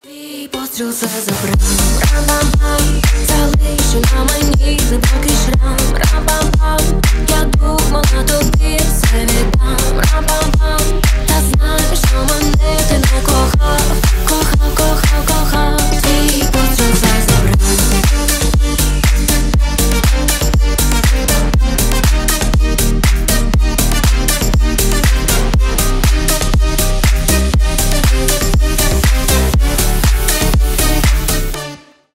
Поп Музыка
клубные # кавер